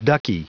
Prononciation du mot ducky en anglais (fichier audio)
Prononciation du mot : ducky